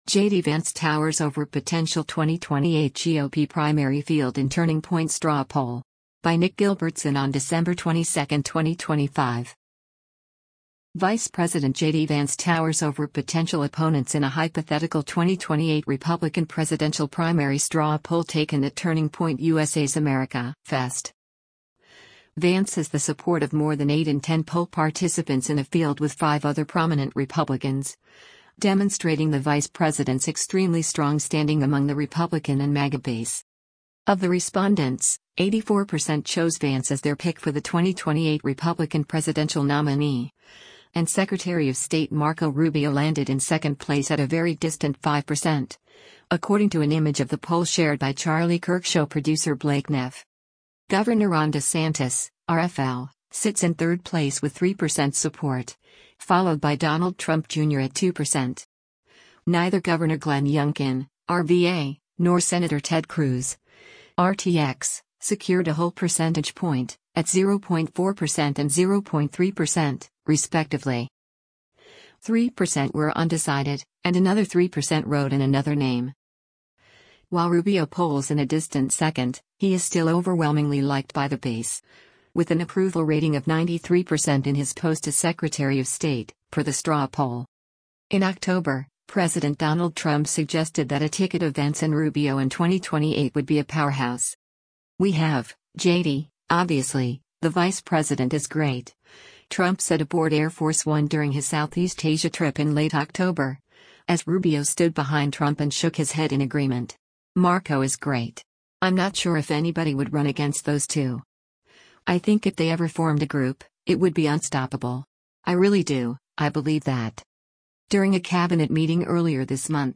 Vice President JD Vance speaks on the final day of Turning Point USA's annual America